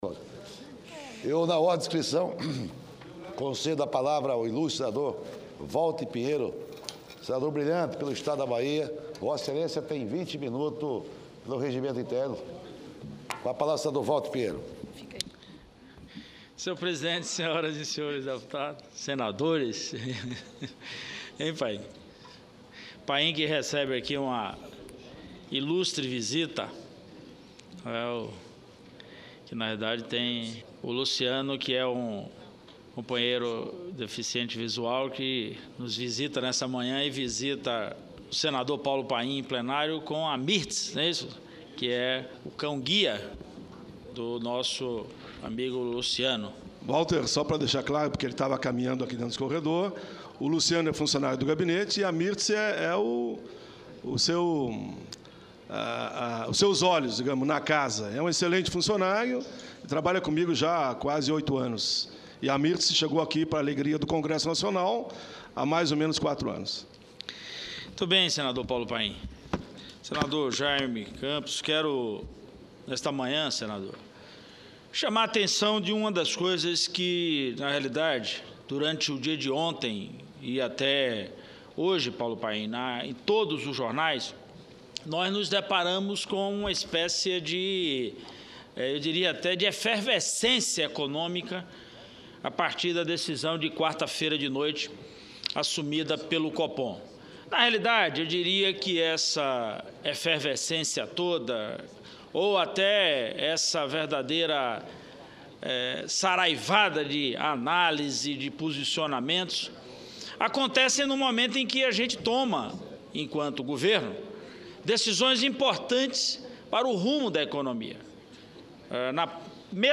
Senador Walter Pinheiro (PT-BA) chamou atenção, em Plenário, nesta sexta-feira (02/09), sobre a decisão de quarta-feira do Copom de reduzir a Taxa Selic. Comentou também a entrega do Projeto de Lei Orçamentária feita esta semana pela ministra do Planejamento, Miriam Belchior.